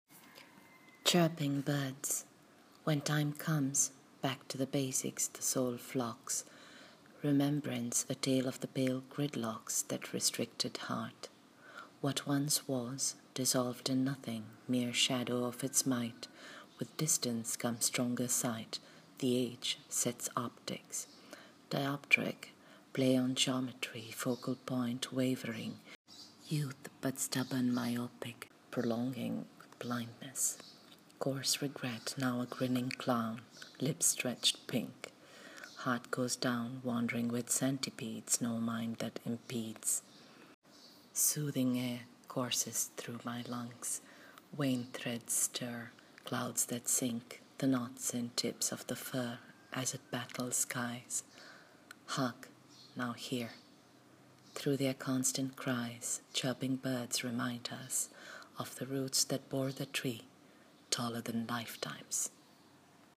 Reading of the poem:
chirping-birds-poem.m4a